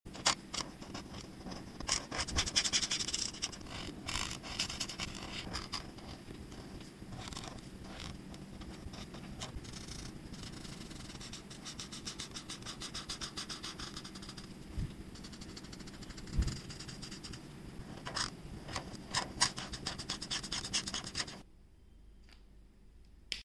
ASMR Colors: Coloring a Among sound effects free download
Watch every smooth glide of color and enjoy the relaxing sounds that make ASMR art so addictive.